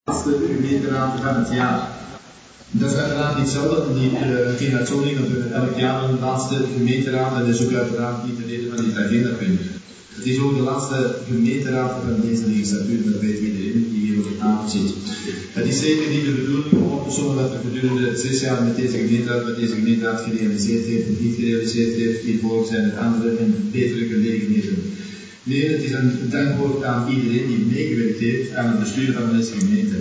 speech
Burgemeester Harrie Hendrickx bracht in zijn slottoespraak hulde aan tal van afzwaaiende raadsleden.
harriespeech.mp3